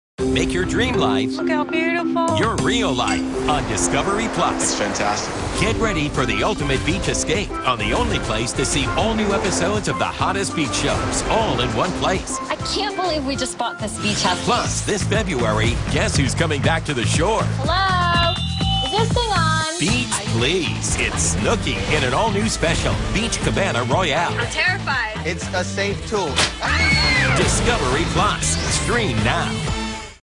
Joe CiprianoBeach Shows on Discovery+Promos Download This Spot